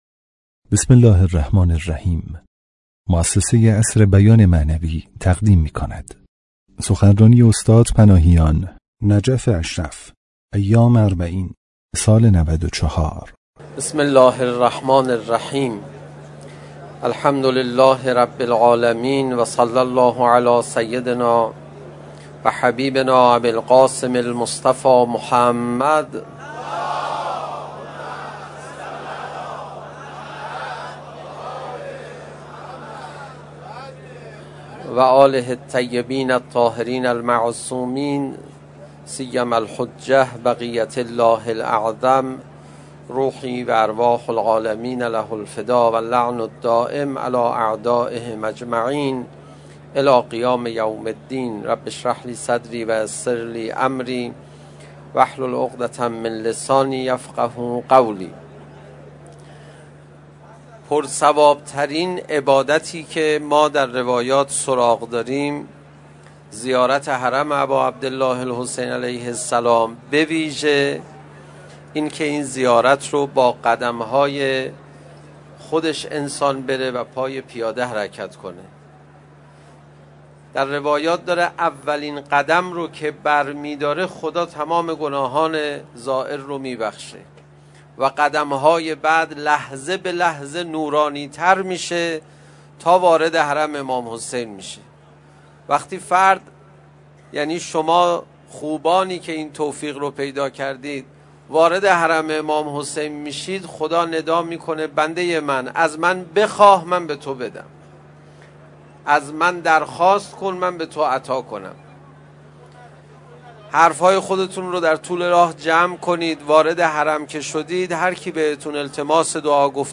مکان: نجف اشرف. پل ثورة ‌ العشرین. ابتدای مسیر پیاده ‌ روی به سمت کربلا
موضوع سخنرانی: پر ثواب‌ترین عبادت مستحب